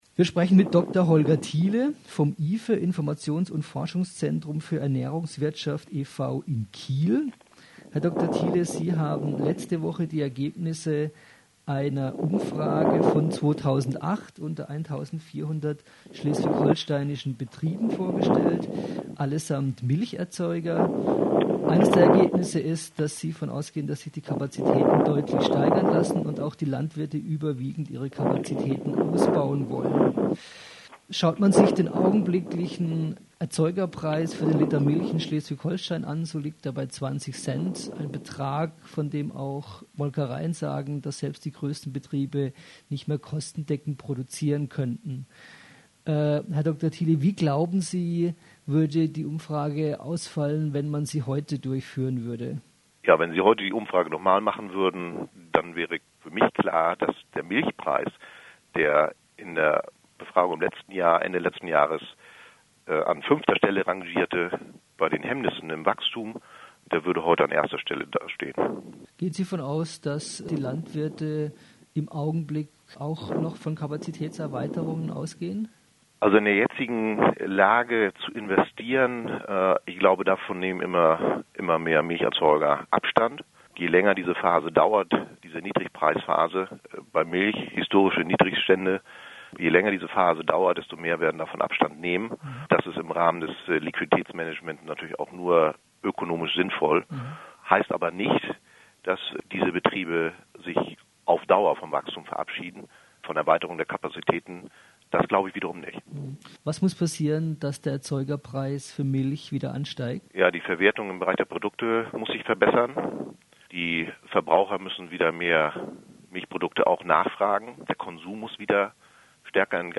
Telefoninterview